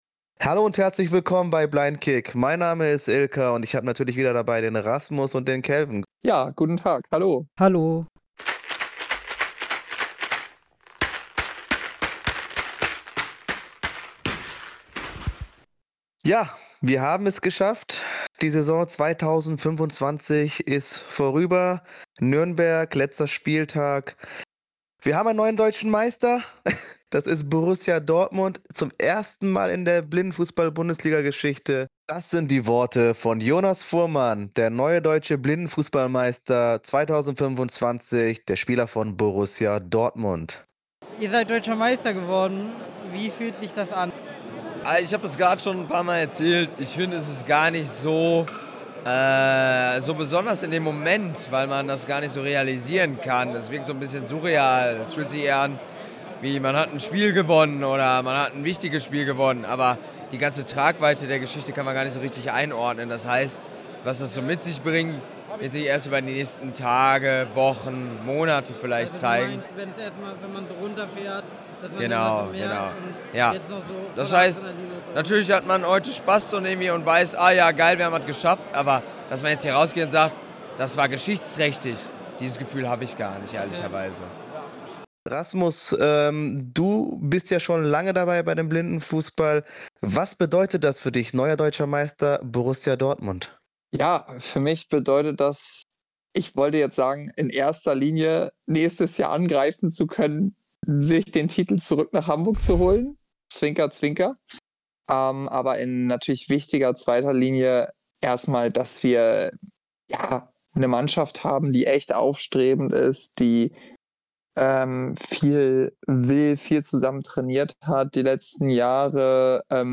viele spannende Momente in der Folge blicken wir zurück auf die letzte Saison und haben dabei auch Live Eindrücke eingefangen